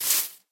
grass4.ogg